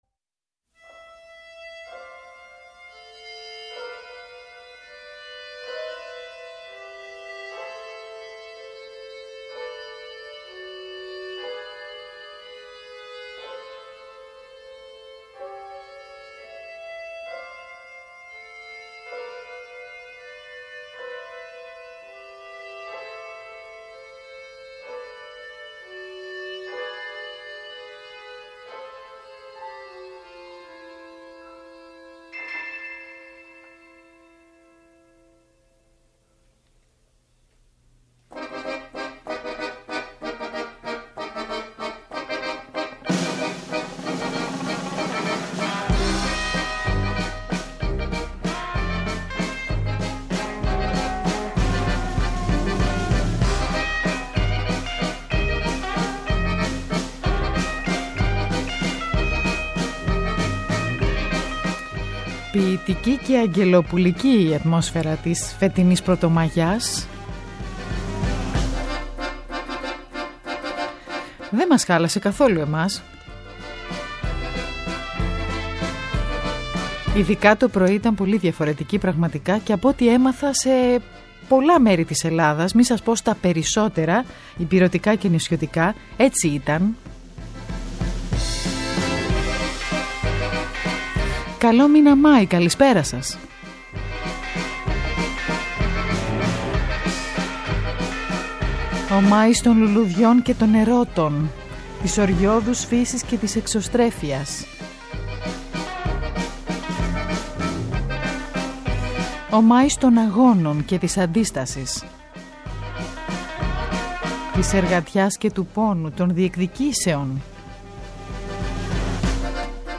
Σήμερα καλεσμένος ο Σκηνοθέτης Δήμος Αβδελιώδης με αφορμή τη θεατρική εκδοχή της “Ελένης” του Γιάννη Ρίτσου και του “Επιταφίου Περικλέους” του Θουκιδίδη.